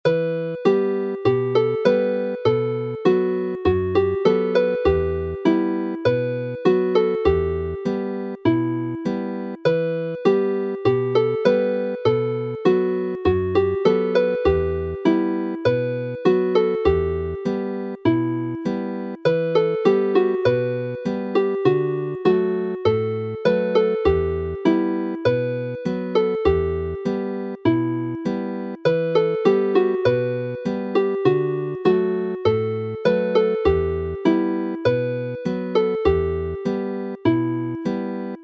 Although it is named as a jig, the music is written and played as a polka.
Chwarae'n araf
Play slowly